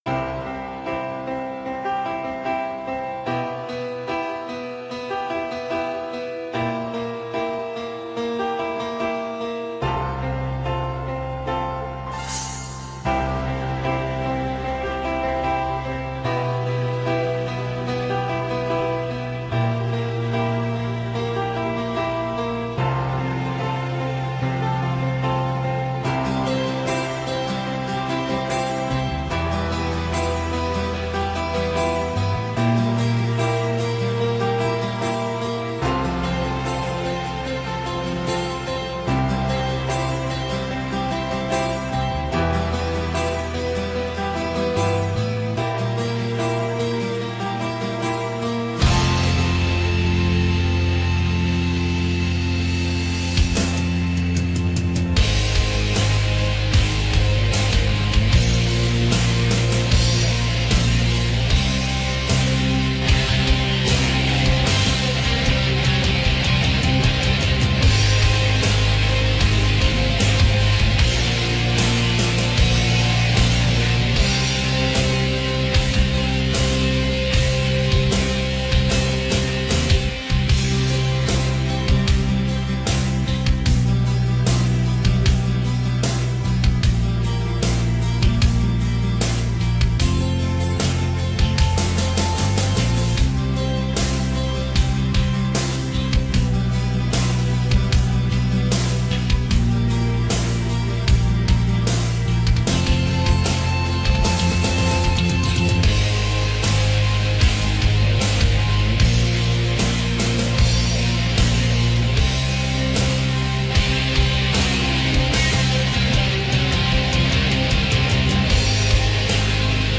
There's no good reason for squealies in the guitar.